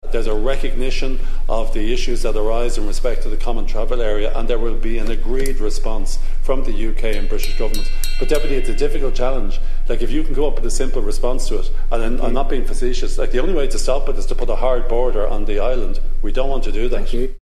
Jim O’Callaghan told the Dáil he can’t export people from Ireland to countries which aren’t willing to accept them back.
Minister O’Callaghan addressed Sinn Féin’s criticism by saying there aren’t easy solutions to illegal migration: